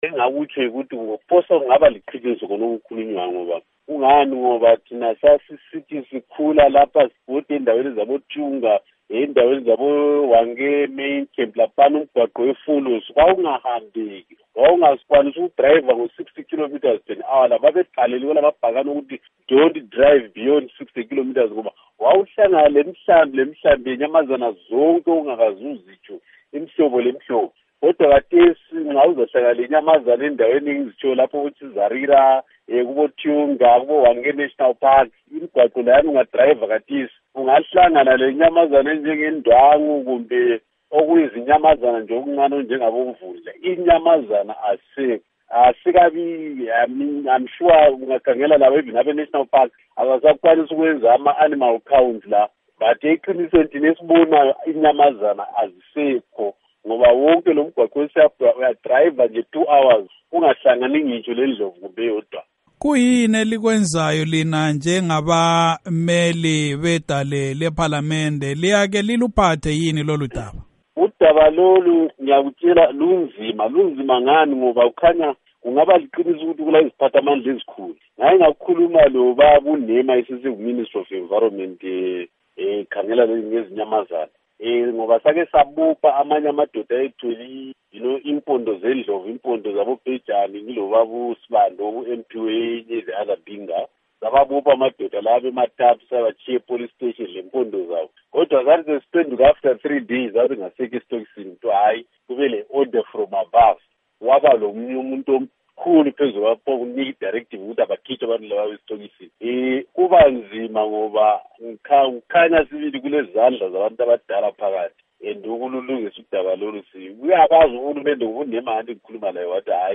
Ingxoxo loMnu. Joel Gabhuza